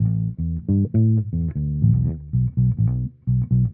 bass bof.wav